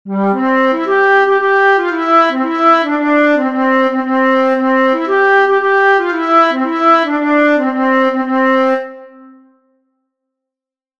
Musiche digitali in mp3 tratte dagli spartiti pubblicati su
Raccolta e trascrizioni di musiche popolari resiane